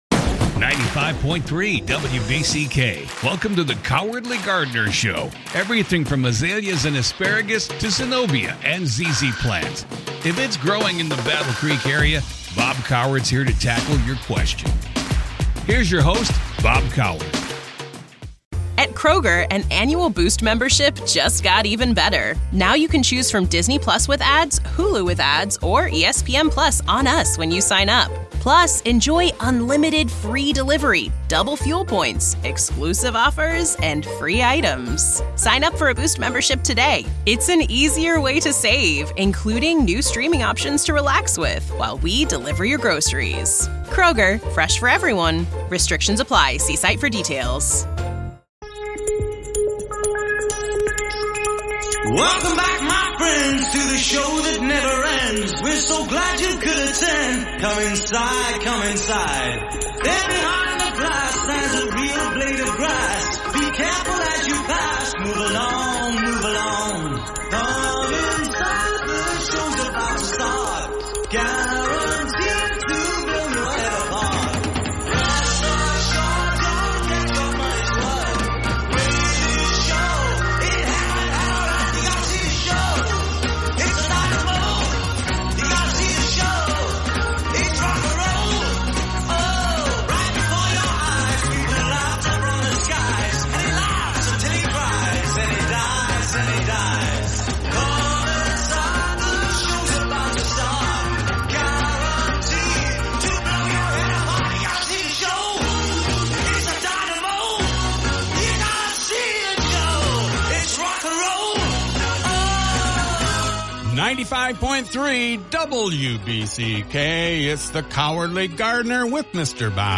answers your gardening questions every Saturday morning at 10am on 95.3 WBCK.